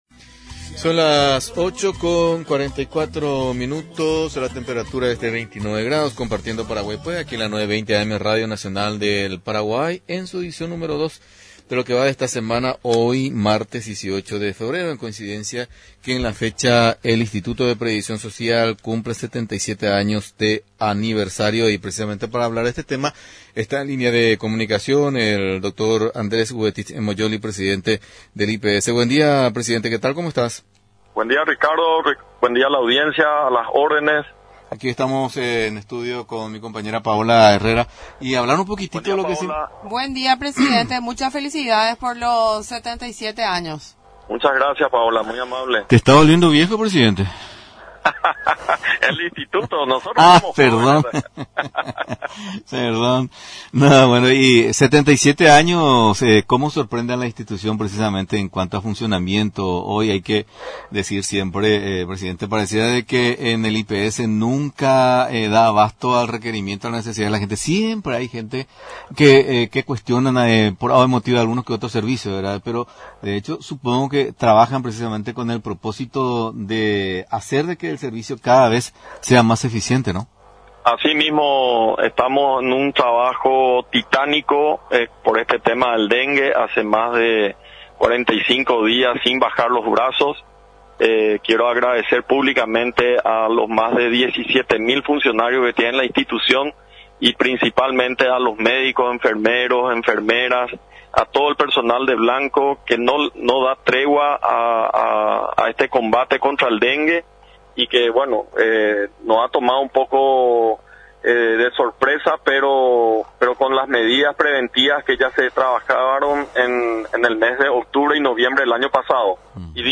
En comunicación con el titular del IPS Andrés Gubetich mencionó que el personal de blanco del Instituto de Previsión Social (IPS)no da tregua en el combate contra el dengue.